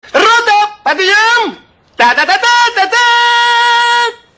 На этой странице собраны звуки утреннего подъёма в армии — команда «Рота, подъём!» и сопутствующие шумы казарменной жизни.